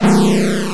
8位视频游戏的声音 " 铃声激光低 - 声音 - 淘声网 - 免费音效素材资源|视频游戏配乐下载
使用SFXR创建